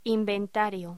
Locución: Inventario
voz